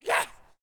femalezombie_attack_05.ogg